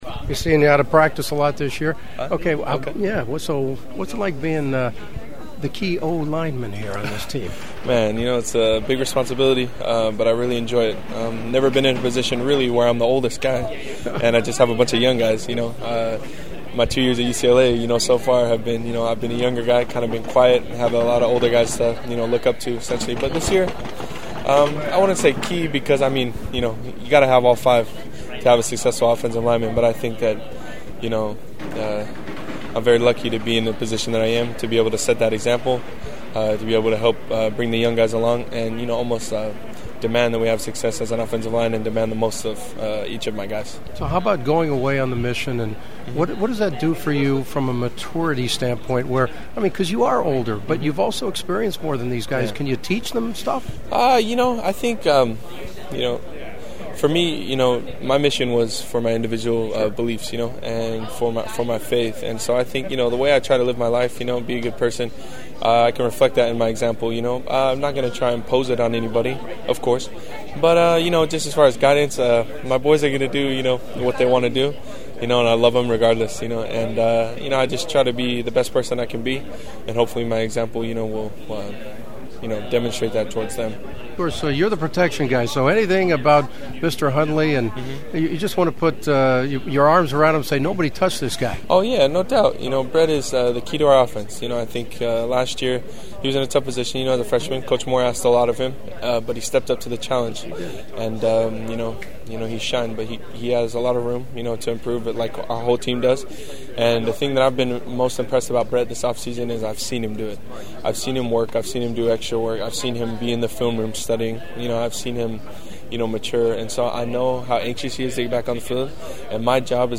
Pac 12 football media at Sony Studios